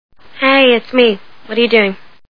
The Sopranos TV Show Sound Bites